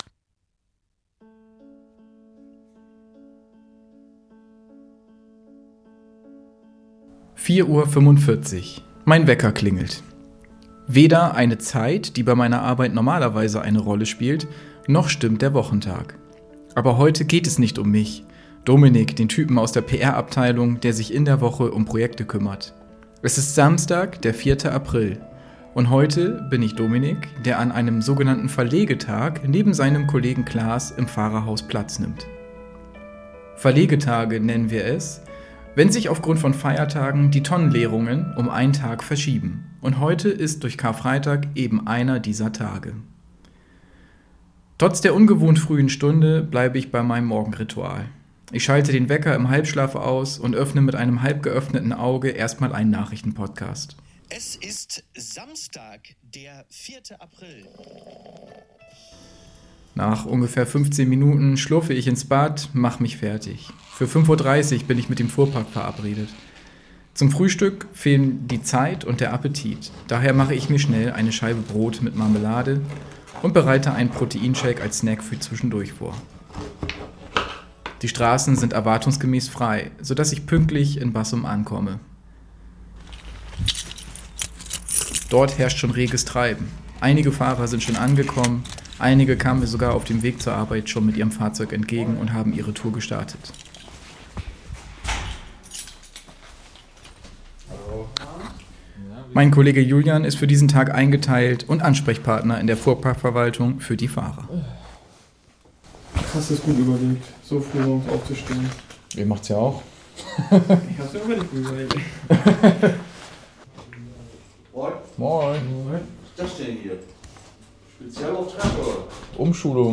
#14 Reportage